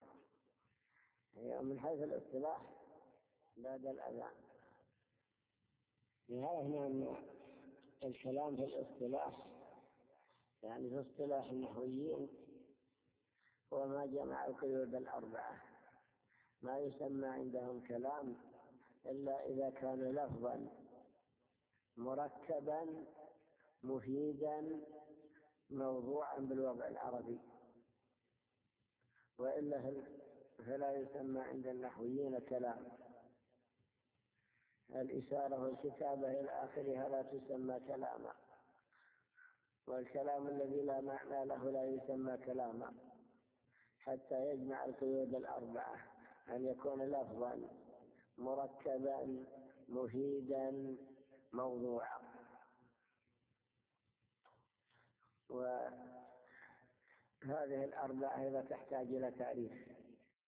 المكتبة الصوتية  تسجيلات - كتب  شرح كتاب الآجرومية الكلام في اللغة والاصطلاح